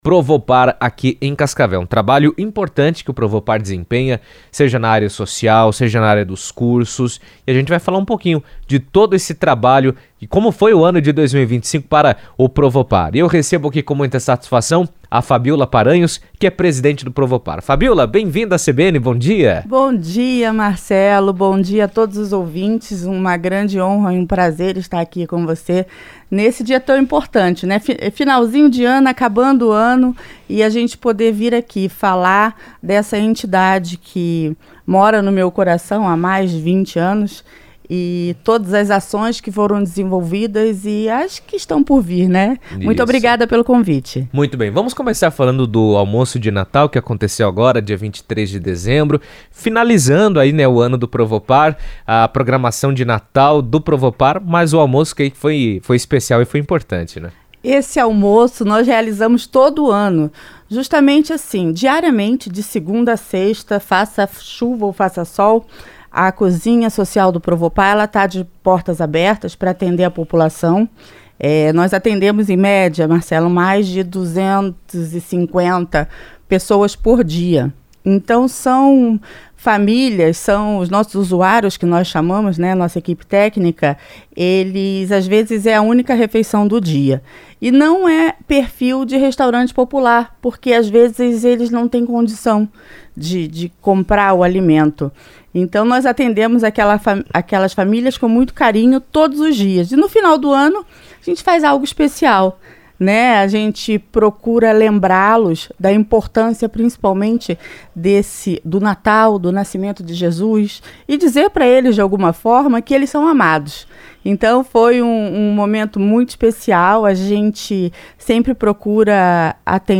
O Provopar de Cascavel encerrou 2025 celebrando os resultados positivos de suas ações sociais, que beneficiaram centenas de famílias ao longo do ano. Em entrevista à CBN